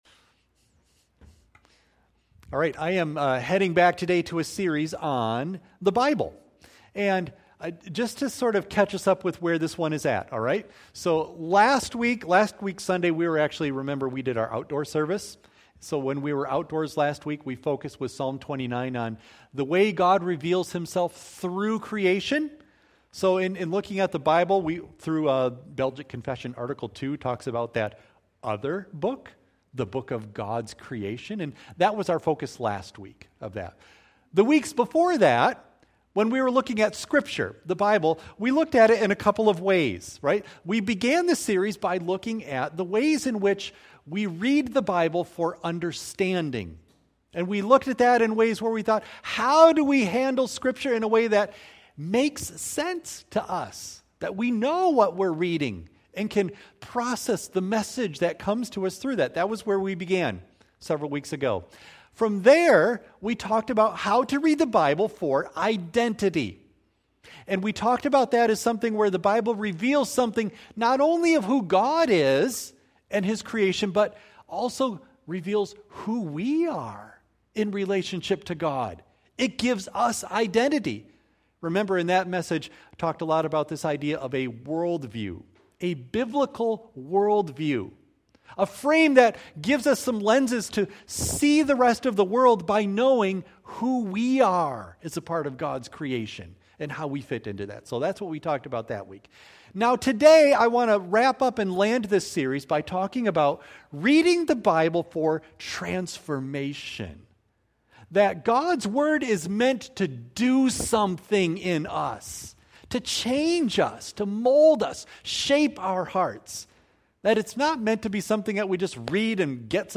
Worship Service